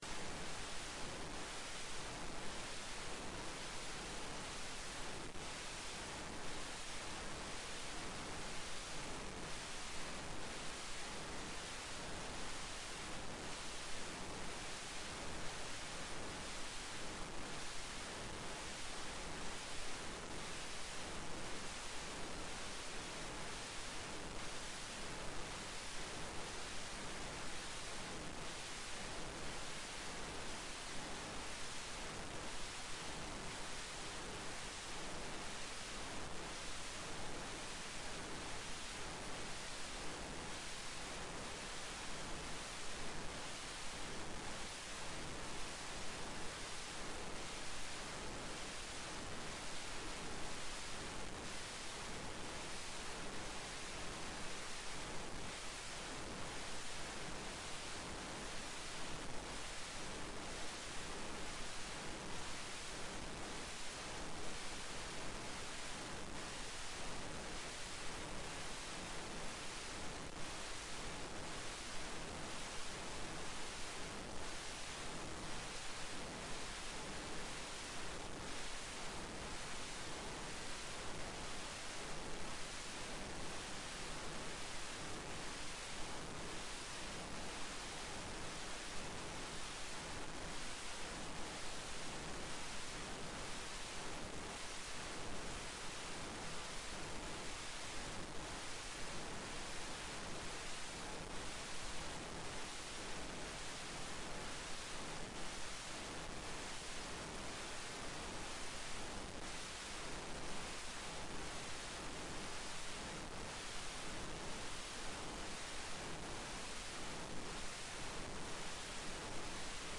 Forelesning 11.2.2020
Rom: Store Eureka, 2/3 Eureka